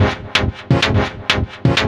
Stab 127-BPM F.wav